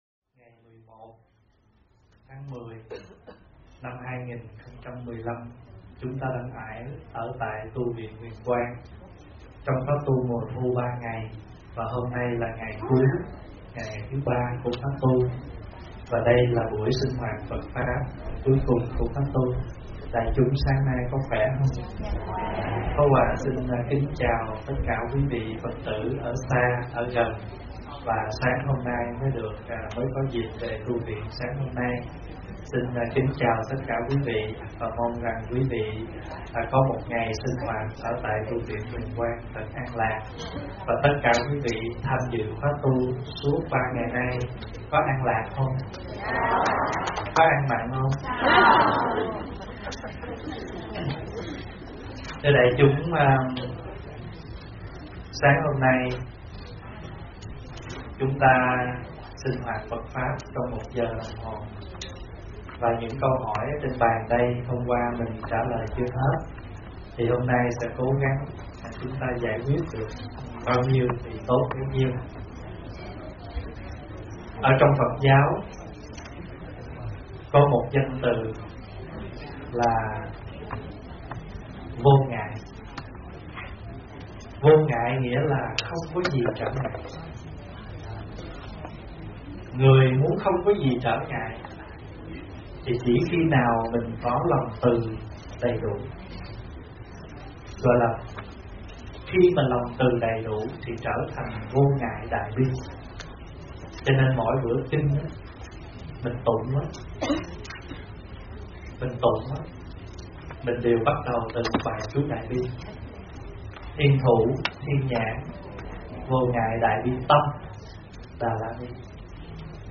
giảng trong khóa tu mùa thu tại tu viện Huyền Quang